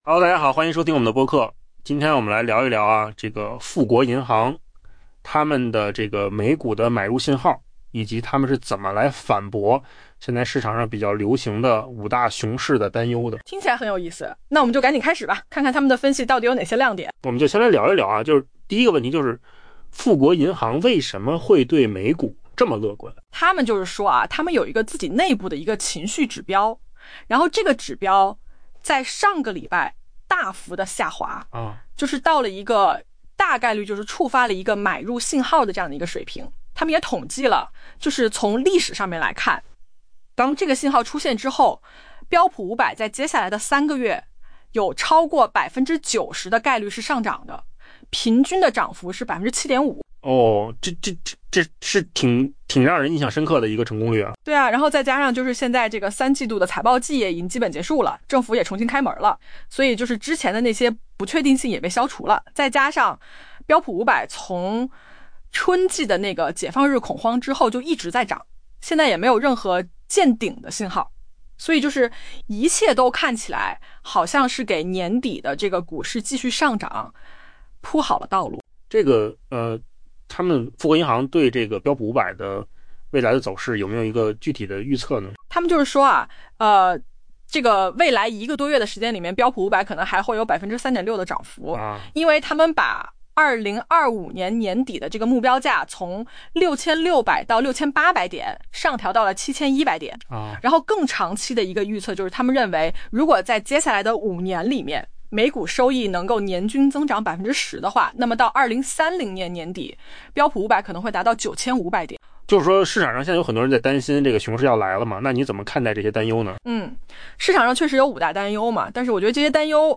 AI 播客：换个方式听新闻
音频由扣子空间生成